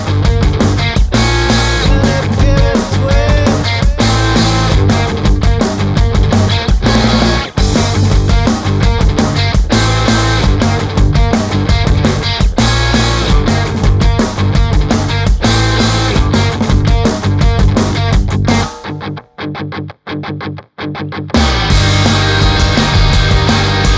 no Backing Vocals Rock 4:23 Buy £1.50